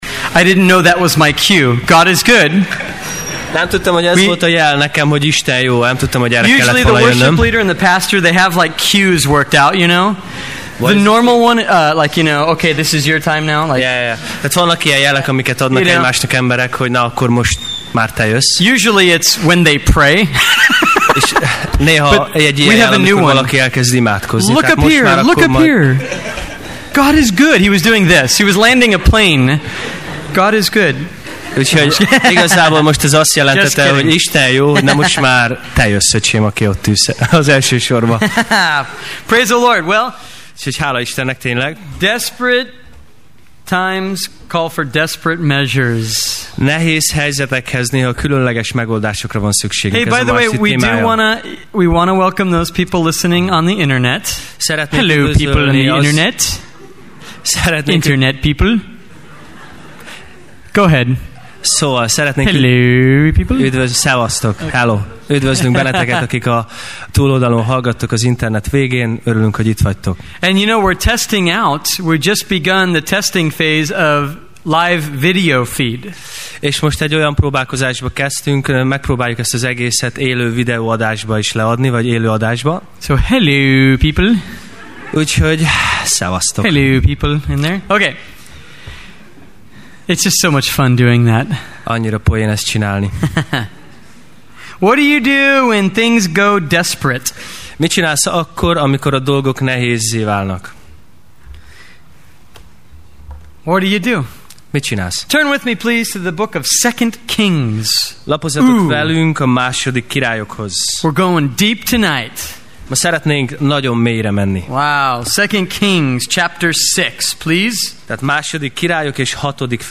Sorozat: Tematikus tanítás
6 Alkalom: Vasárnap Este « 7+ Este